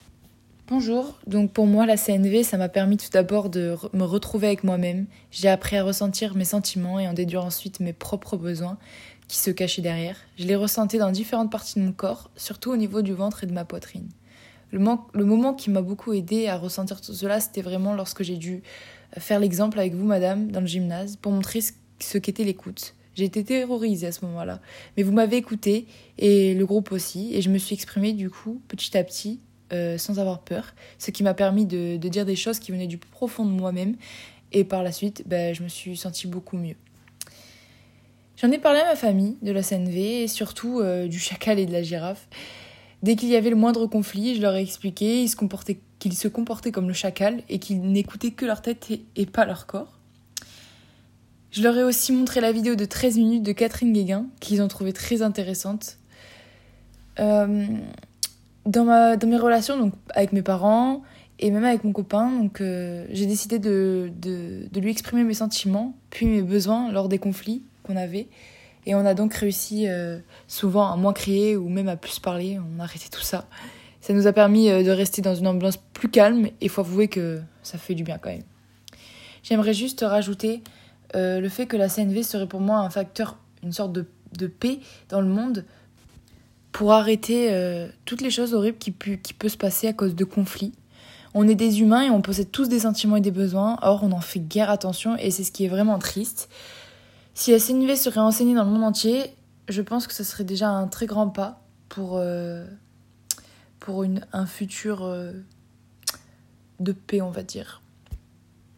Une étudiante témoigne !